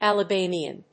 音節Al・a・bam・i・an 発音記号・読み方
/`æləbˈæmiən(米国英語)/